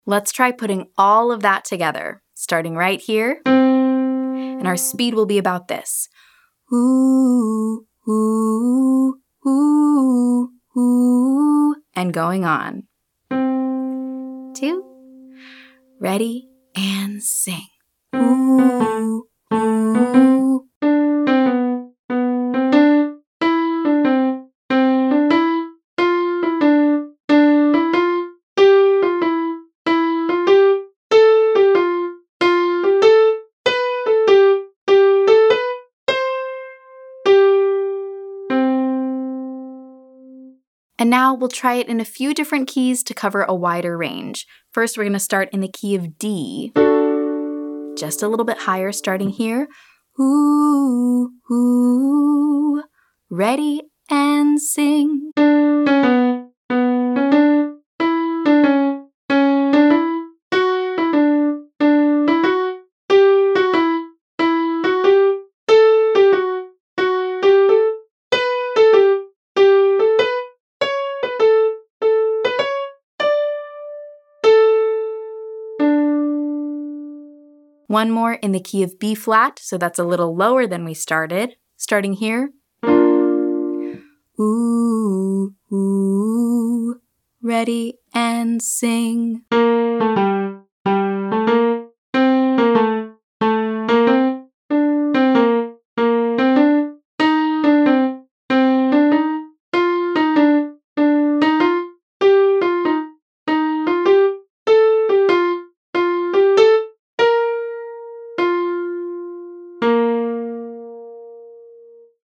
Our exercise will combine descending and ascending patterns, walking up the major scale. It’s a long one, so we’ll learn it listen and repeat style first!
Now, we’ll try it in a few different keys to cover a wider range.
Exercise: 3-note riffs up the scale, starting with DTL, LTD; RDT, TDR; etc; end with D S D